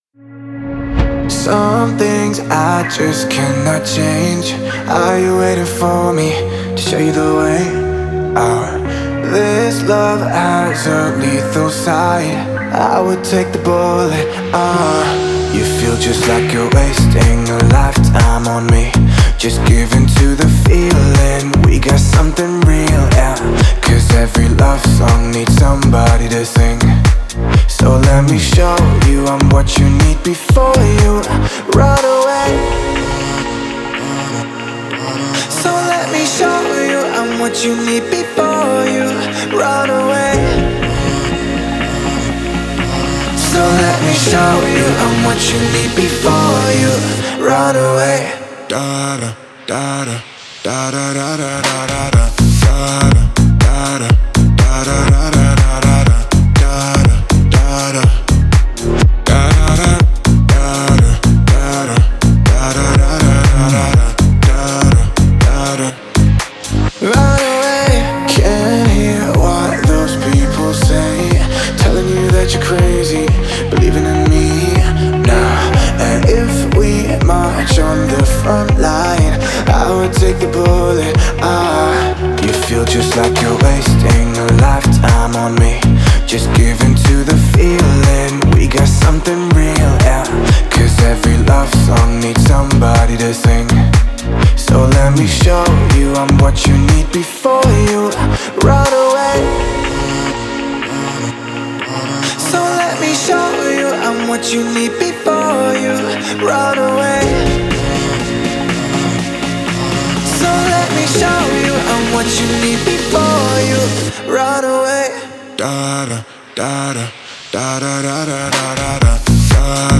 это захватывающая электронная композиция в жанре EDM